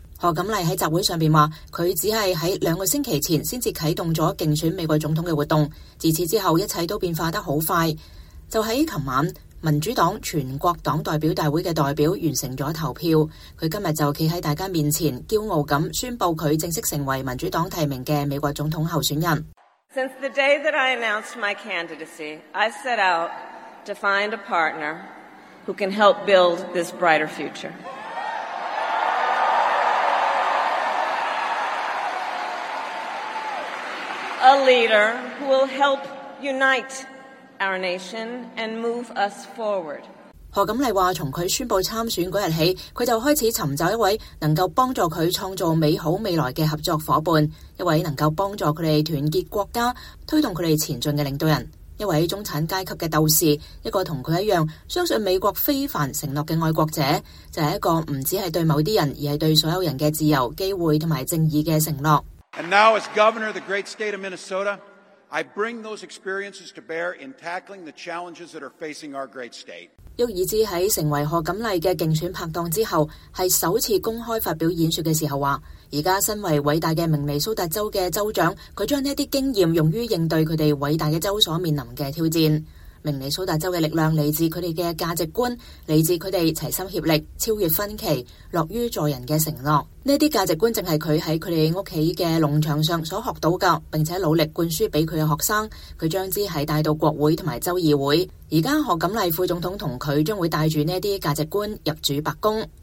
民主黨總統候選人賀錦麗在費城首次和副手沃爾茲發表競選演說